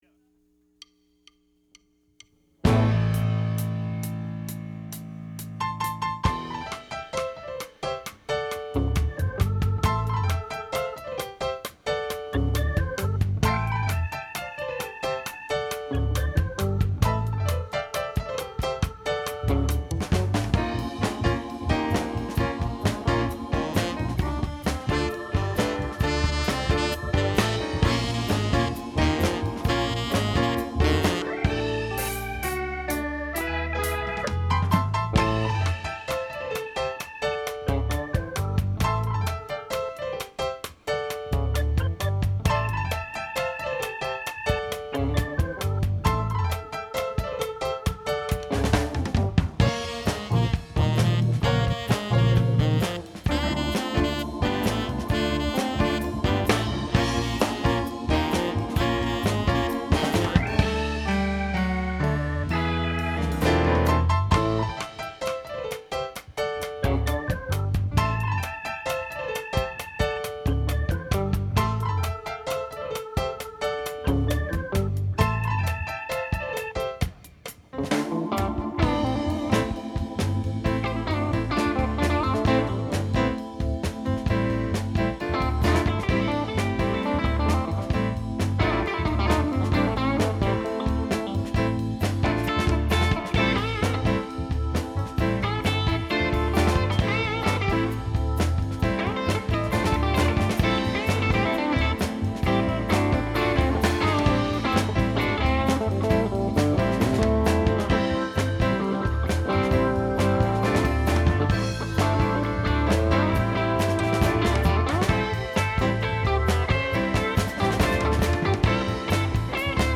lead vocals, percussion, guitar
keyboards, vocals
saxophone
bass guitar
drums